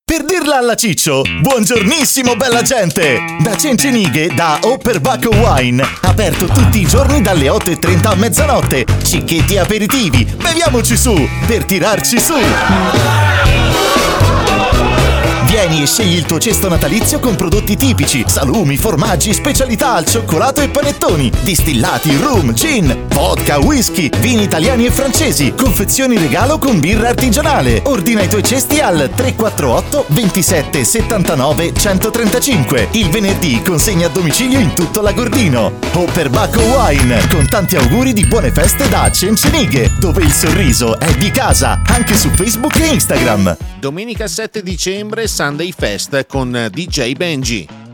LO SPOT ALLA RADIO